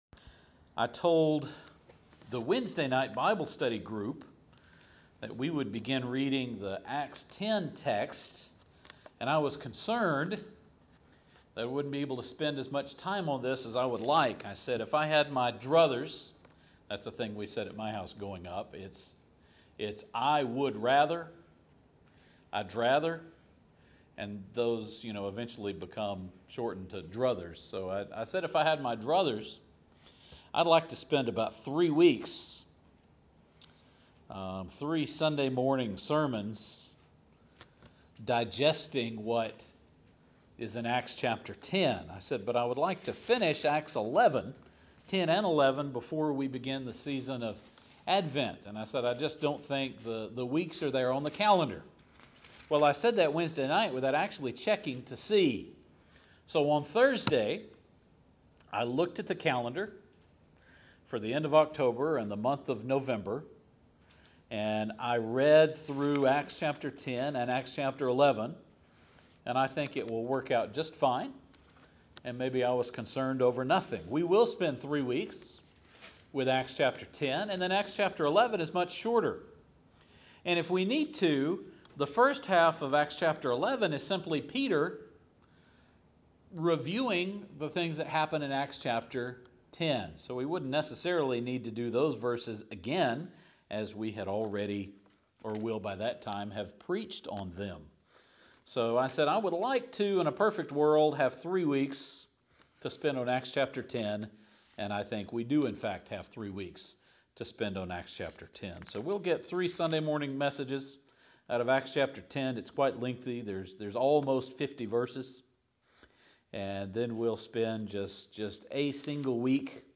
Much like we did with Acts 9 we are going to divide the Acts 10 narrative in three parts. The beauty of the online archive is that you can just click the next sermon to continue and not have to wait a week to see what happens next. We begin with a Roman named Cornelius having a vision about Peter while at the same time Peter has a vision about clean and unclean things.